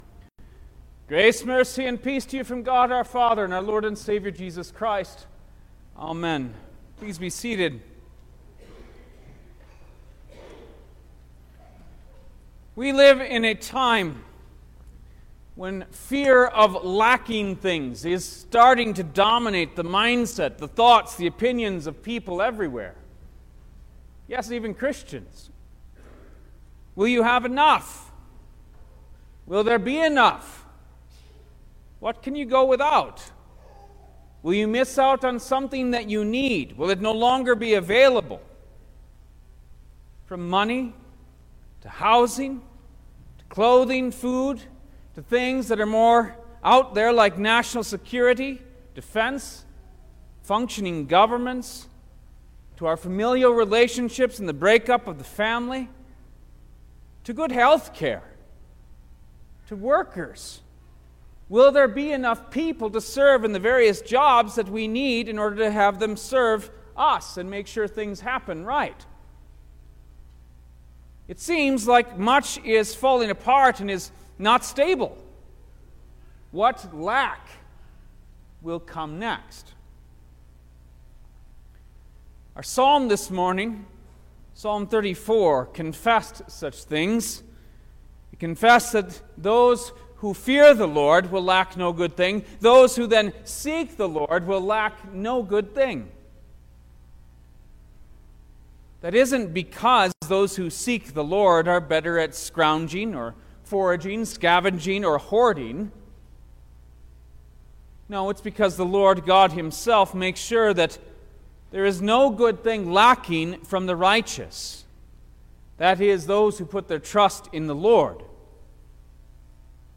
2022 Eighteenth Sunday after Trinity Preacher